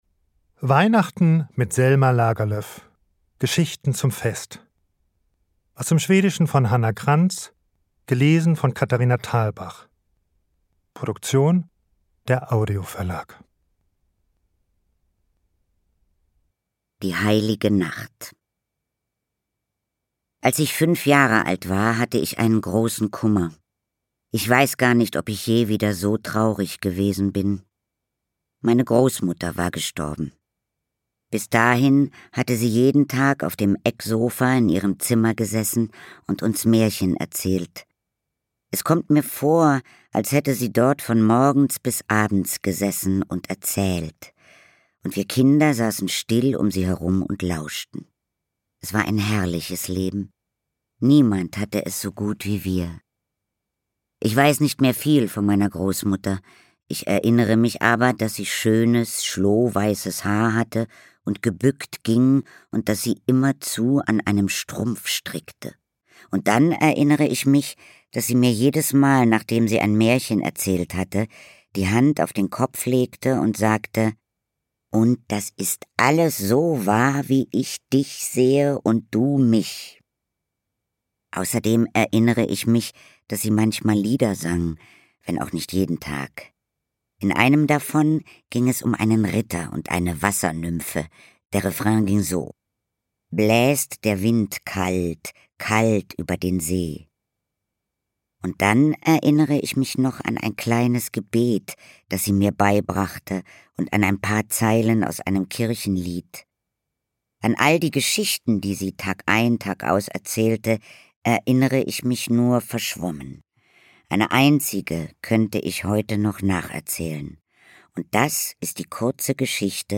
Lesung mit Katharina Thalbach (1 CD)
Katharina Thalbach (Sprecher)
Ungekürzte Lesung mit Katharina Thalbach